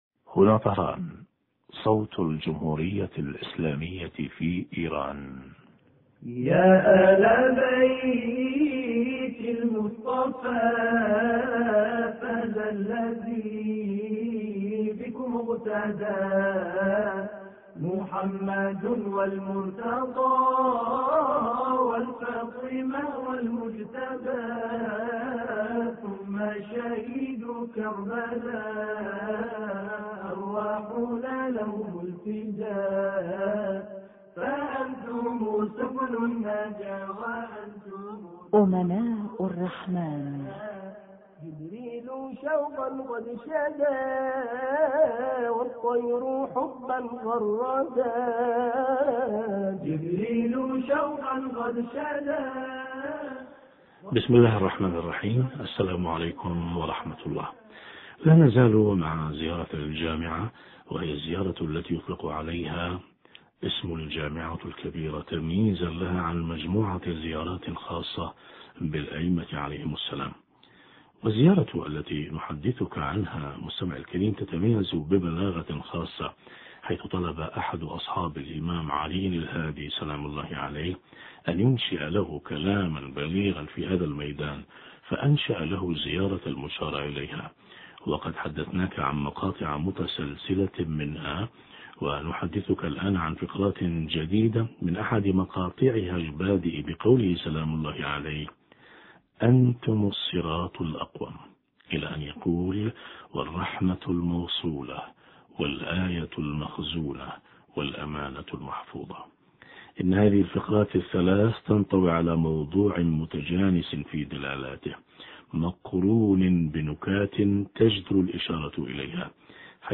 معنى كونهم (عليهم السلام) الرحمة الموصولة والآية المخزونة والامانة المحفوظة حوار